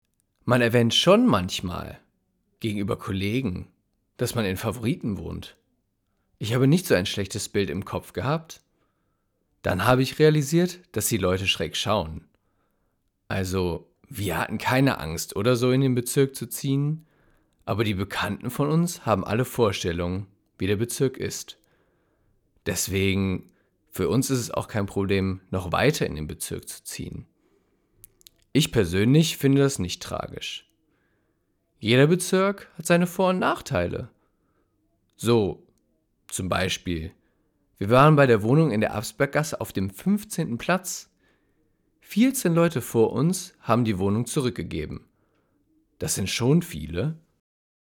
Für die Website wurden die Textstellen nachgelesen: Einige von Jugendlichen und jungen Erwachsenen aus den Wohnhausanlagen, andere von Mitgliedern des Projektteams oder ausgebildeten Schauspielern.